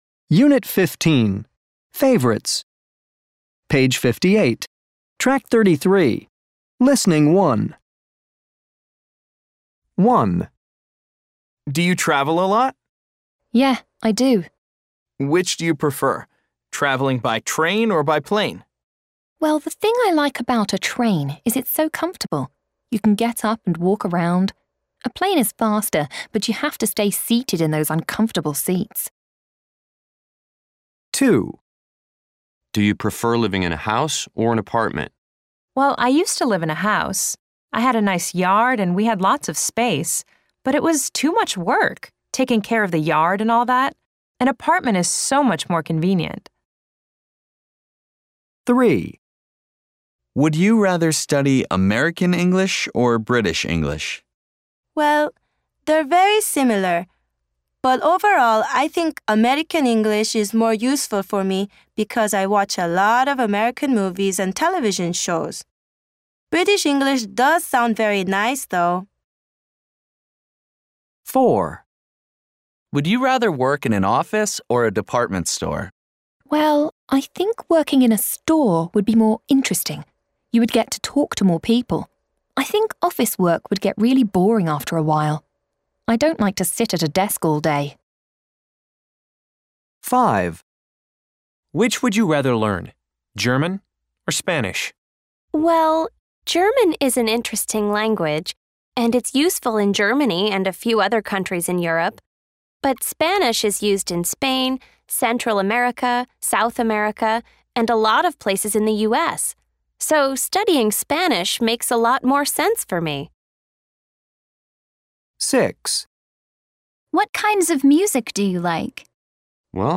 People are talking about their preferences. what do they prefer? listen and circle the correct answer.